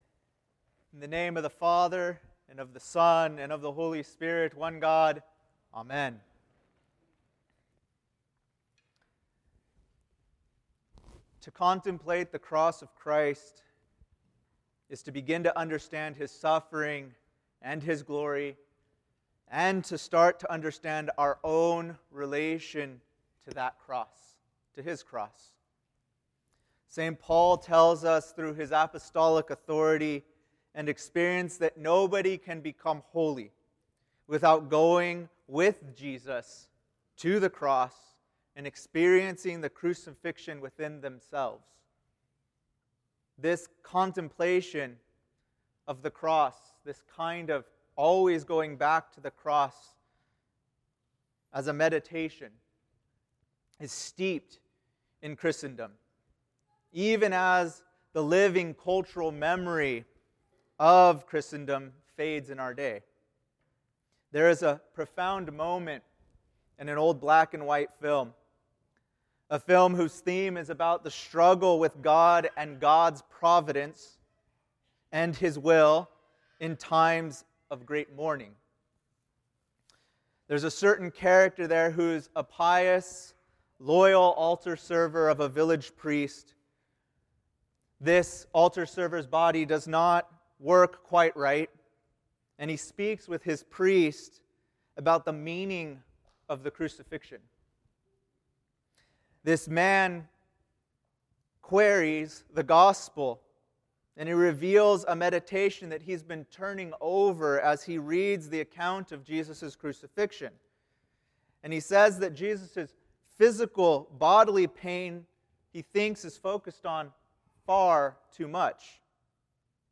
Homilies given during Divine Liturgies at St. Athanasius Orthodox Church.